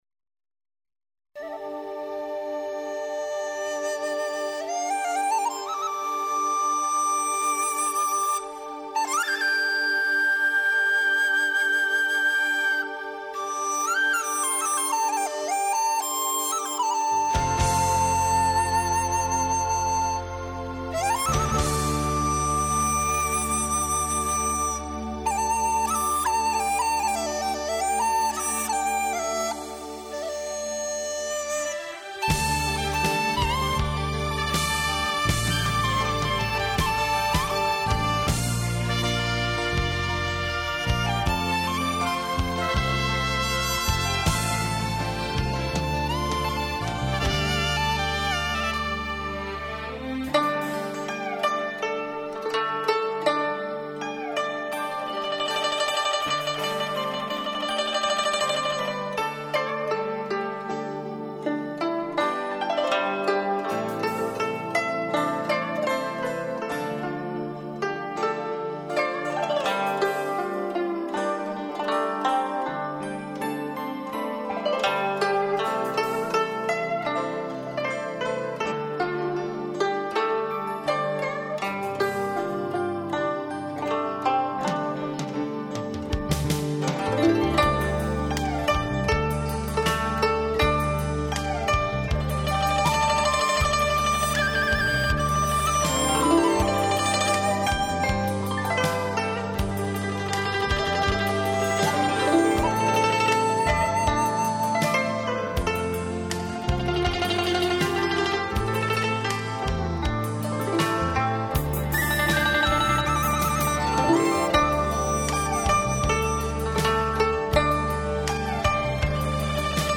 [30/8/2010]古筝演奏【山丹丹花开红艳艳】